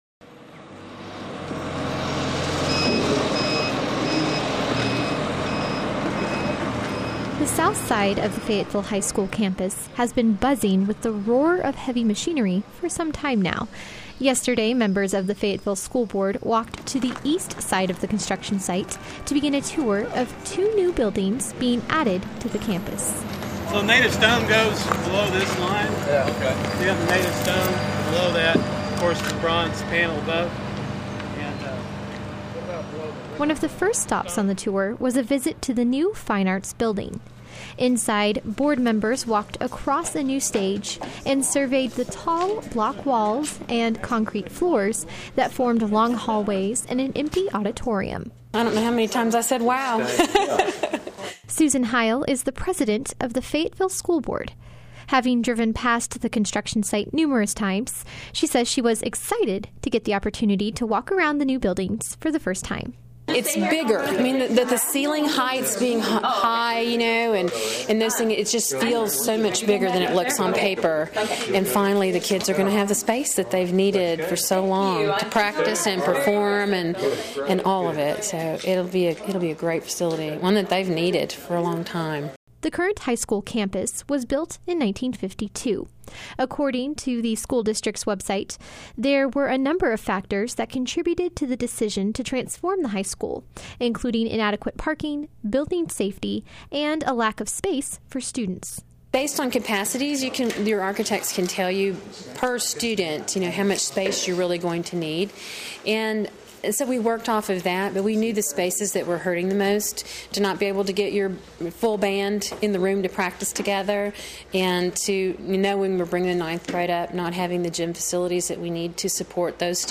She has this report.
FHS_Tour.mp3